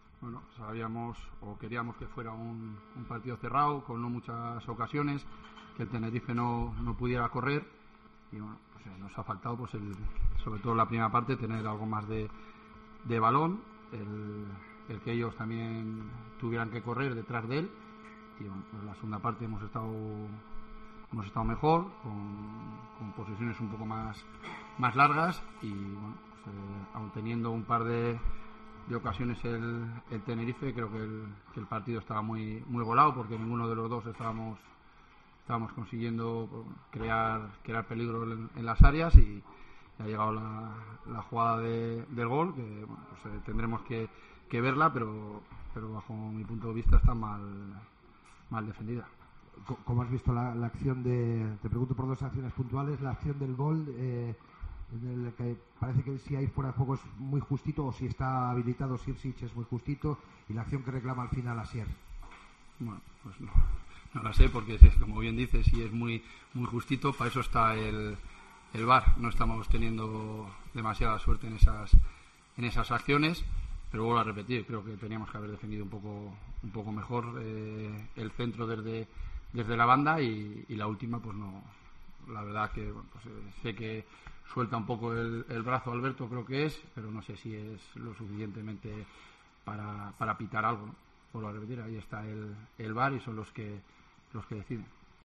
POSTPARTIDO
Escucha aquí las palabras del míster de la Deportiva Ponferradina, Jon Pérez Bolo, tras la derrota 1-0 en tierras canarias ante el Tenerife